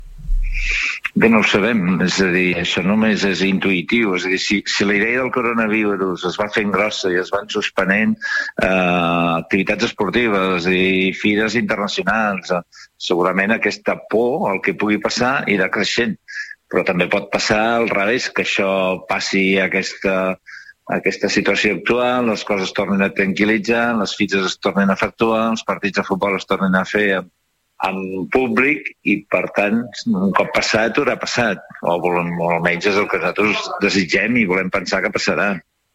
Ho afirmava ahir a l'entrevista del Supermatí